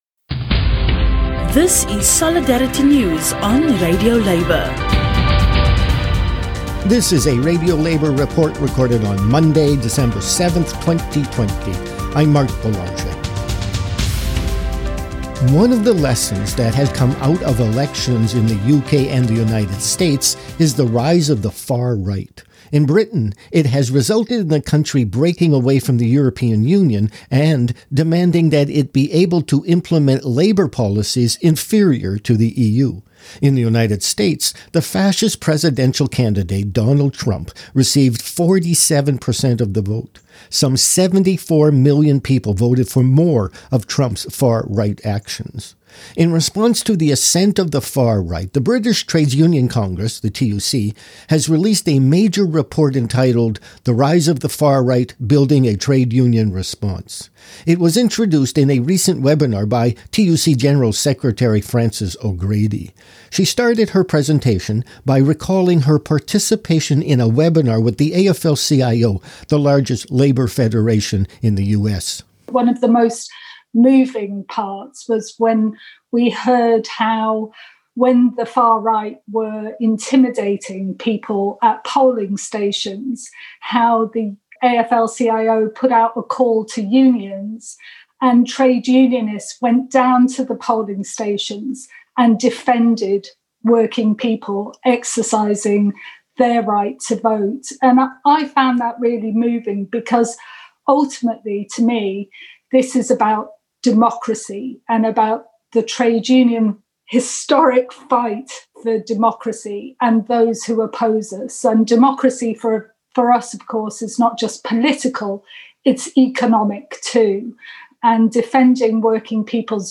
RadioLabour produces daily newscasts of international labour news.
All music played on RadioLabour newscasts is licensed for use by labour organizations.